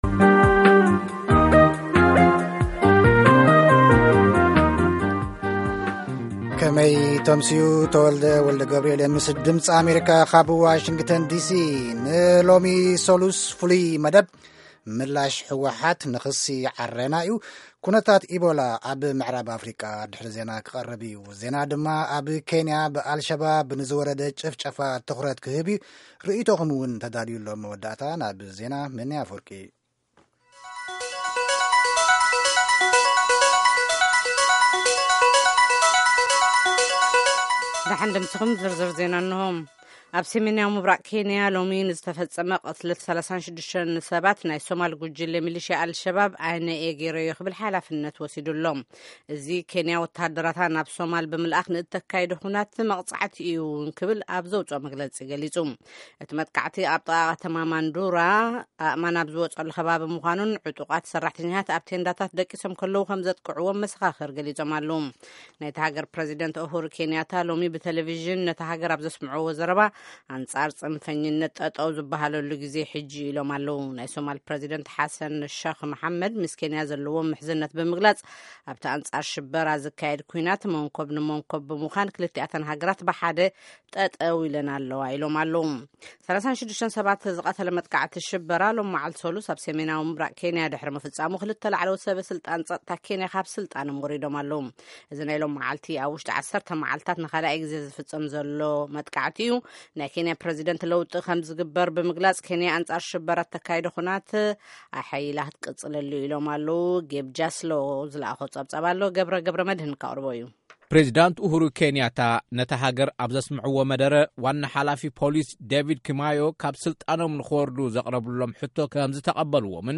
Tigrigna News 1900